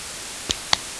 clap-detection
clap-02_noise0.02.wav